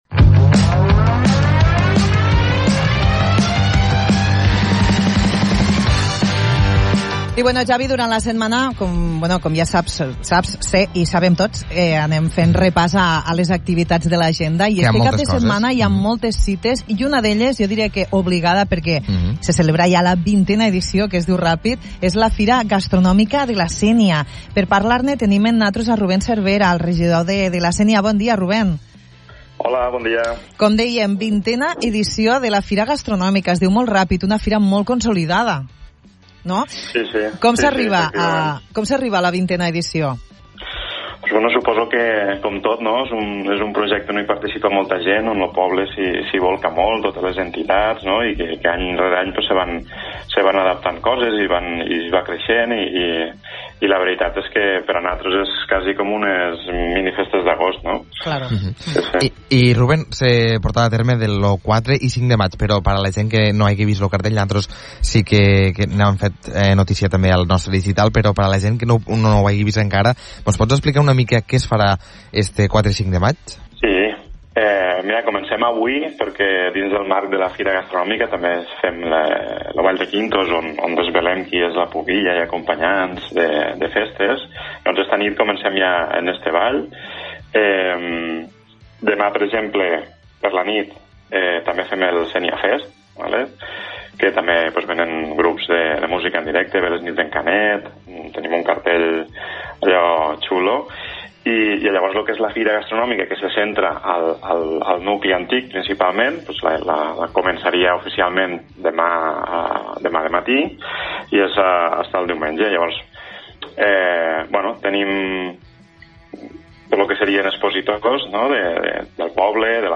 La 20a edició de la Fira Gastronòmica de la Sénia, que tindrà lloc aquest cap de setmana, apropa els visitants a la gastronomia, tradició i festa. Avui al ‘De Bon Matí’ en parlem amb el regidor de Turisme Rubèn Cervera que ens explica què hi ha programat per aquests dies al municipi del Montsià.